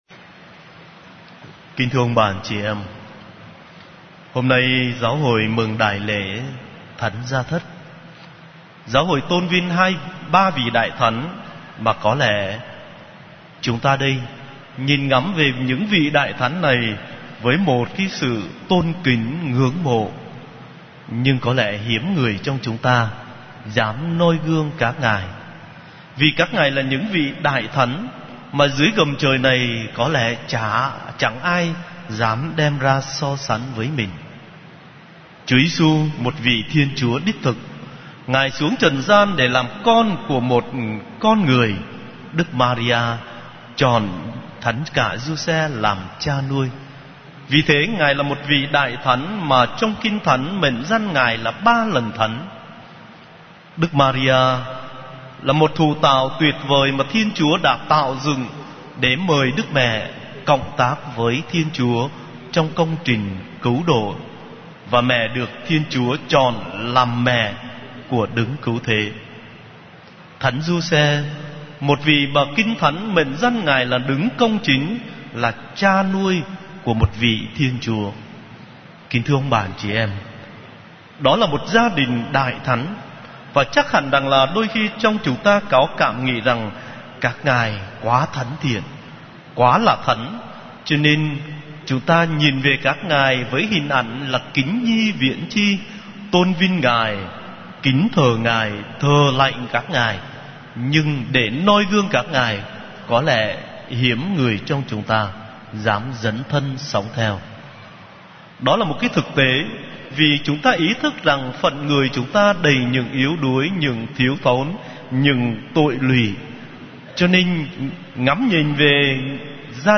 Dòng nhạc : Nghe giảng